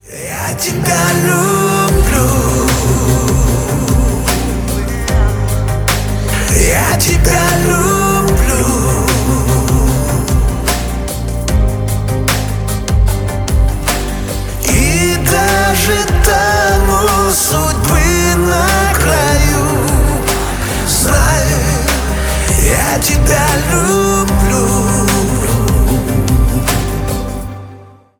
• Качество: 320 kbps, Stereo
Шансон